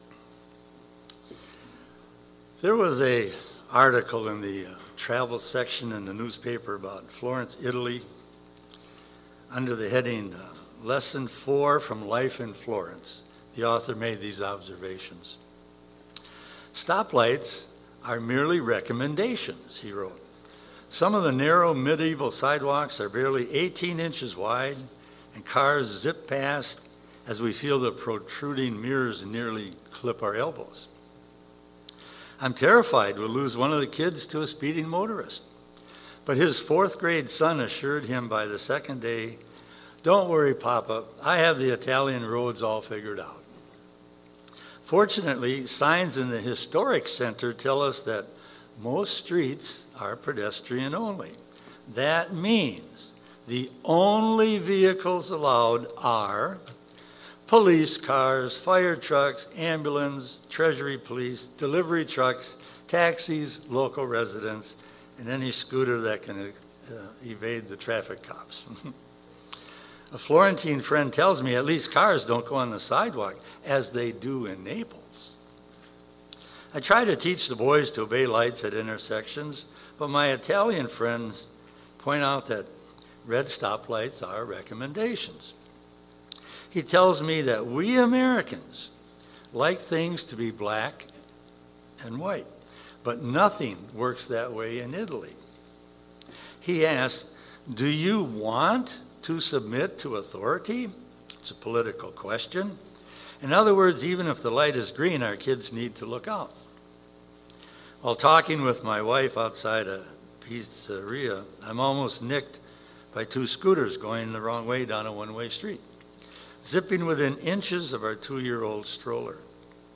Given in Eau Claire, WI